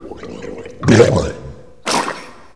Something nasty just arrived.